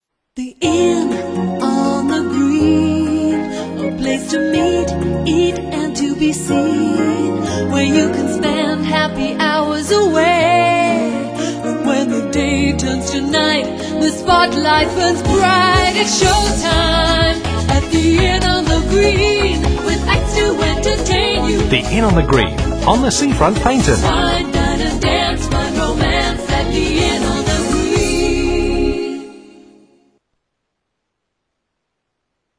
# *gives up ever doing spoof jingles again*